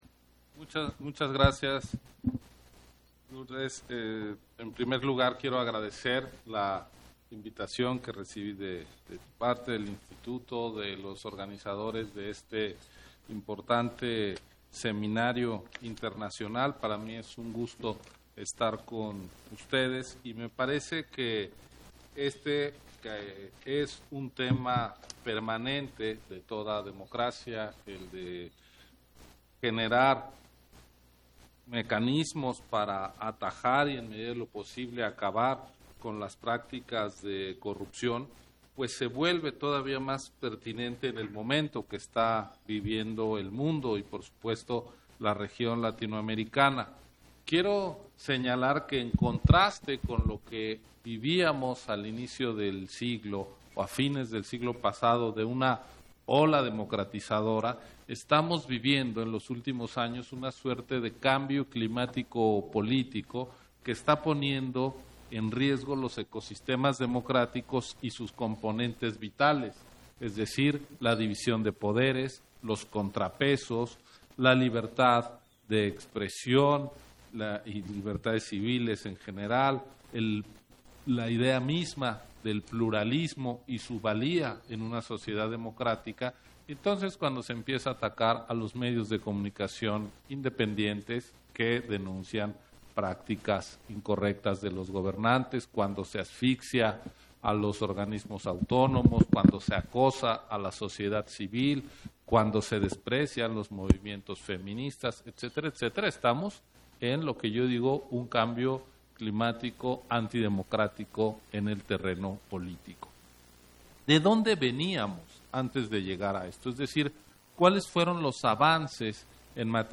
Intervenciones de Ciro Murayama, en la mesa Dinero y elecciones: Dilemas de la rendición de cuentas, 10º Seminario Internacional, Corrupción y política en América Latina